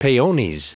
Prononciation du mot peones en anglais (fichier audio)
Prononciation du mot : peones